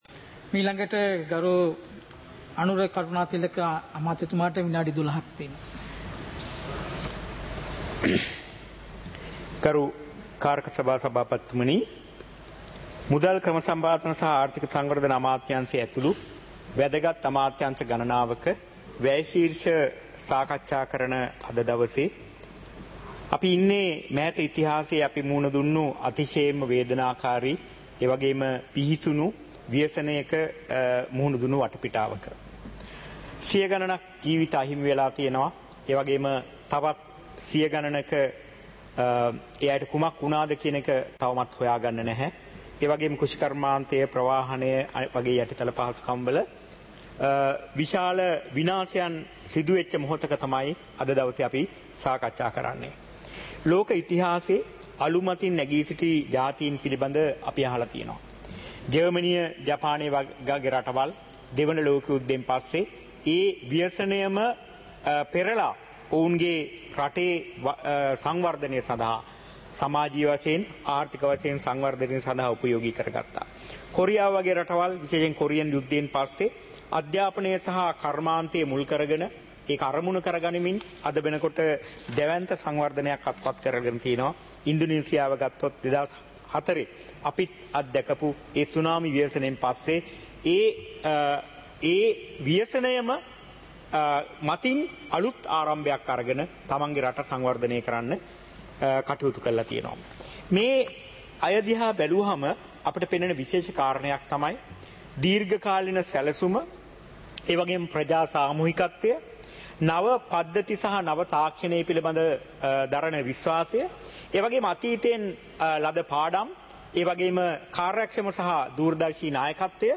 சபை நடவடிக்கைமுறை (2025-12-03)
பாராளுமன்ற நடப்பு - பதிவுருத்தப்பட்ட